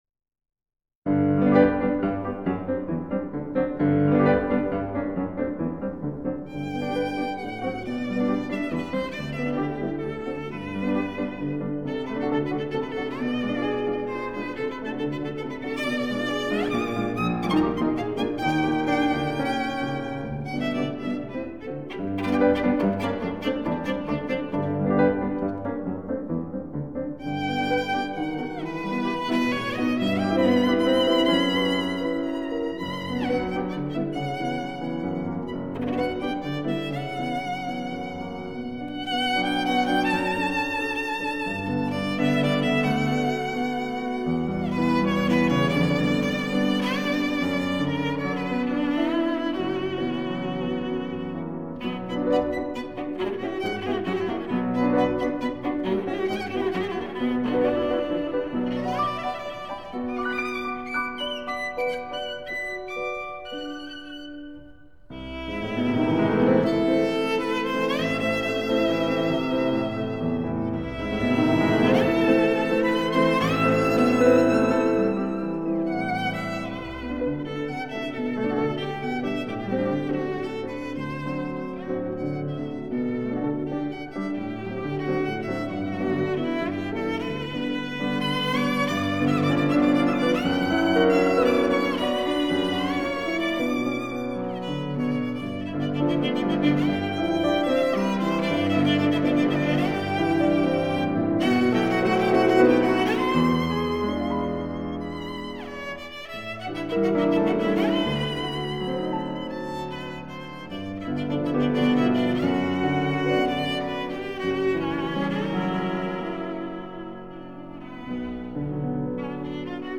Viola
Piano